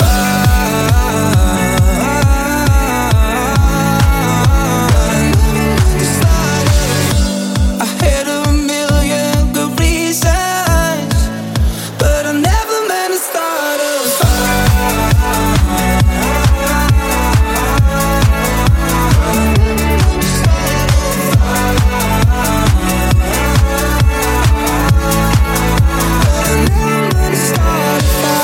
Genere: pop,dance,afrobeat,house.remixhit